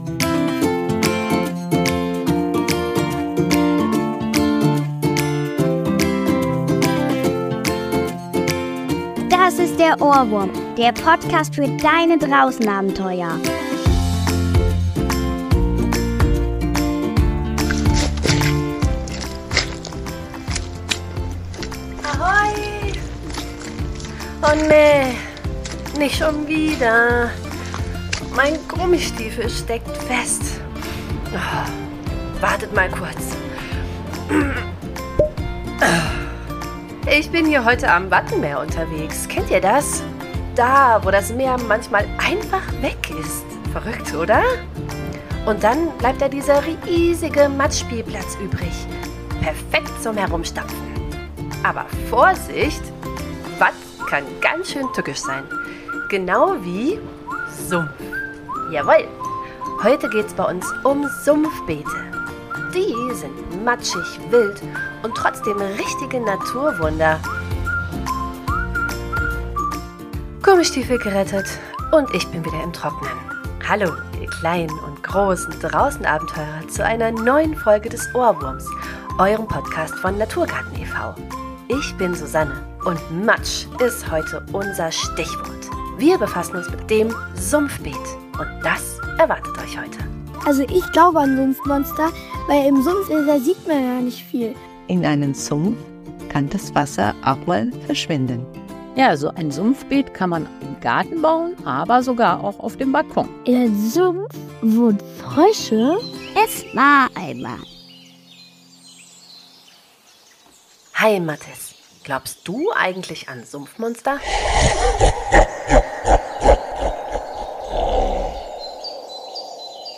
Sumpfbeet ~ DER OHRWURM – Dein Podcast für Draußen-Abenteuer – mit Kindern für Kinder Podcast